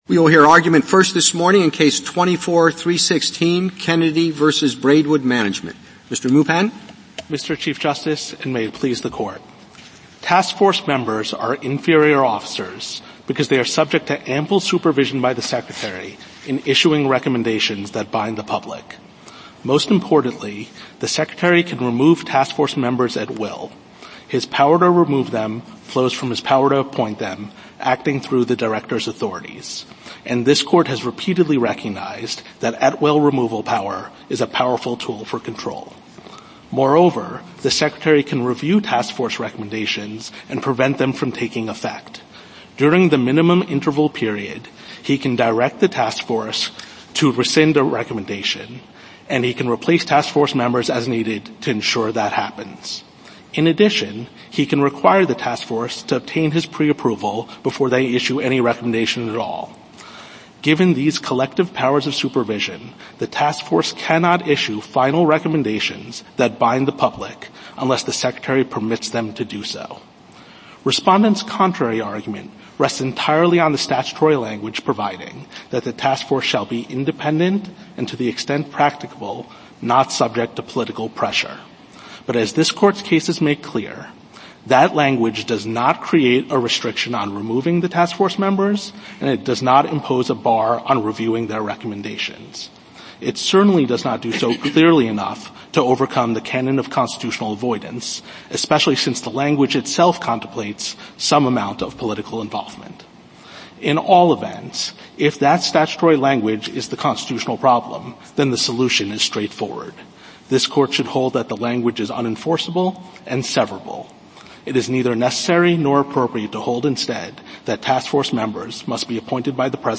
U.S. Supreme Court Oral Arguments